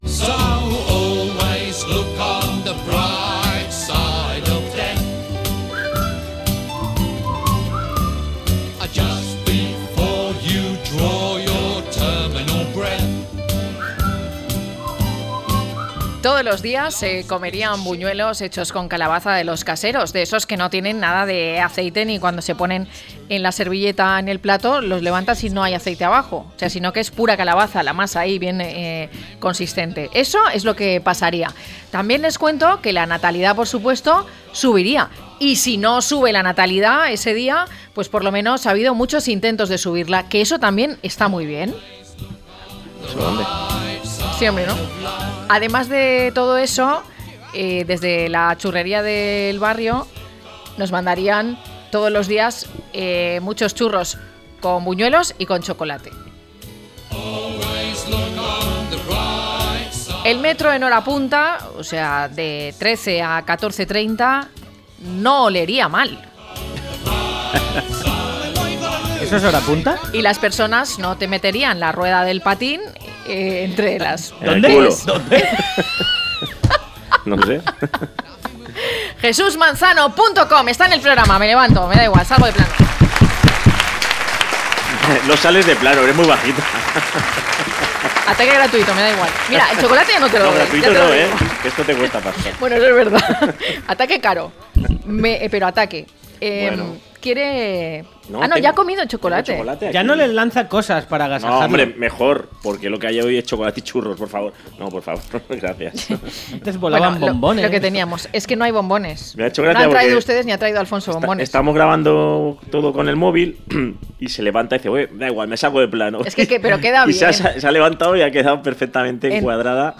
Un cómico que promete un buen rato lleno de humor en el que nos cuenta qué no debemos hacer si somos una persona mayor y no queremos llamar la atención en el médico.